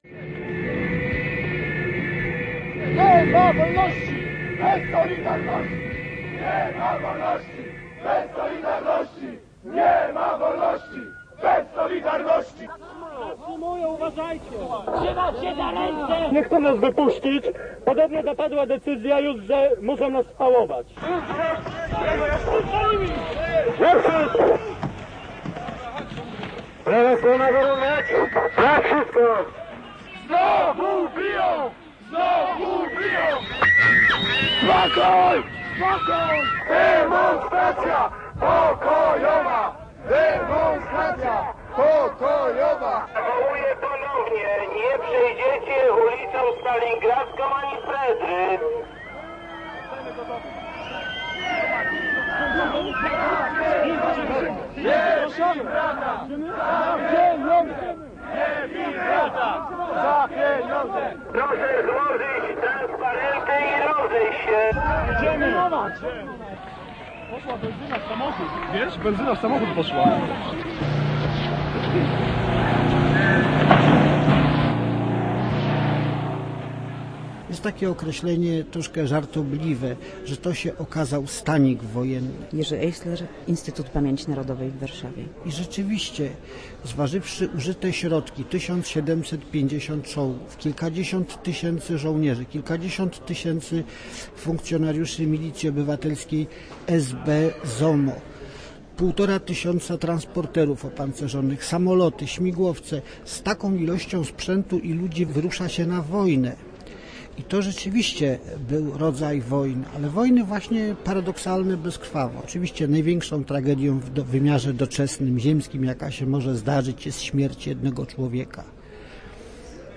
Nikt nie widział - reportaż